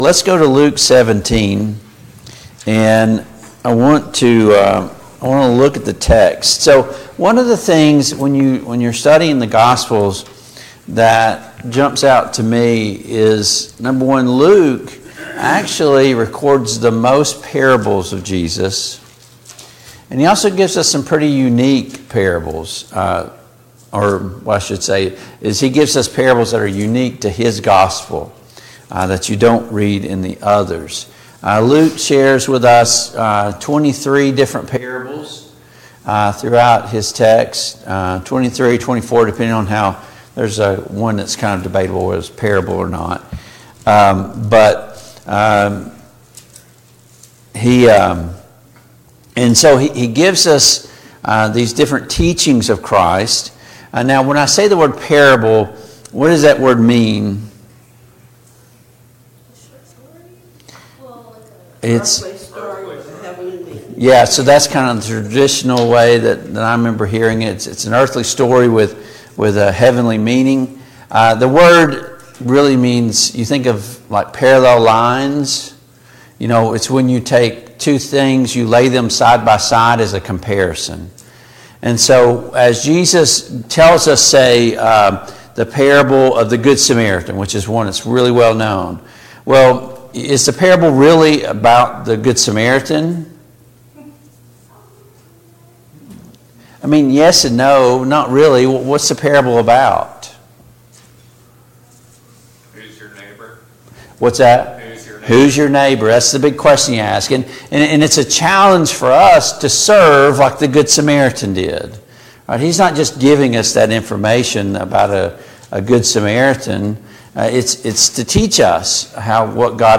Service Type: Sunday Morning Bible Class Topics: Gratitude , Thankfulness